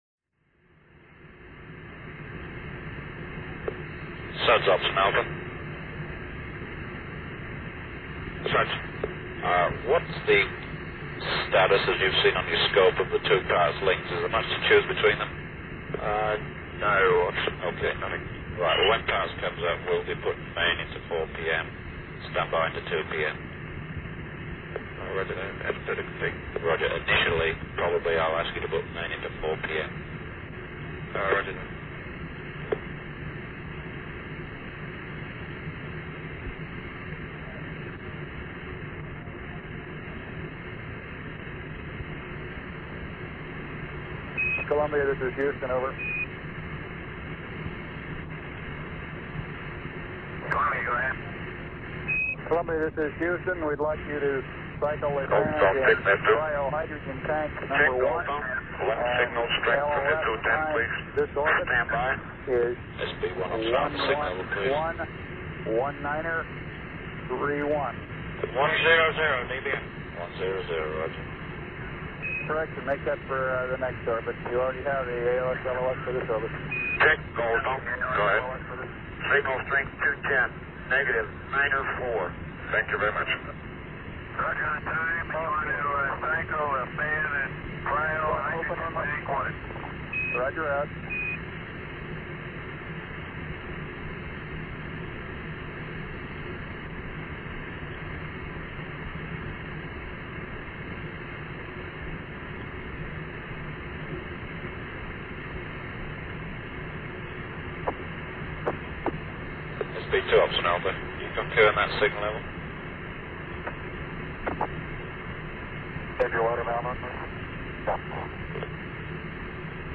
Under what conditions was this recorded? The synchronisation could have drifted by a few seconds by the end of the tape, but it is fairly close. Apollo_11_EVA_Honeysuckle_Net1_and_Net2_Alpha.mp3